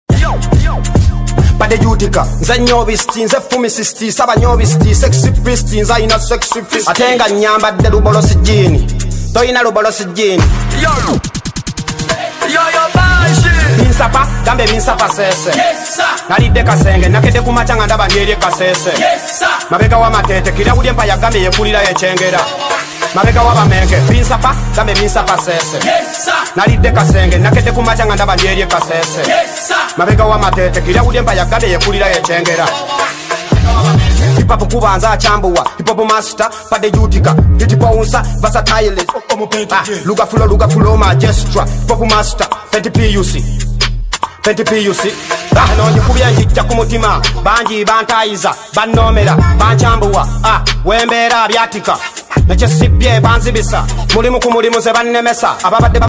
True hip hop